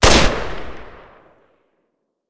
wpn_autorifle_fire.wav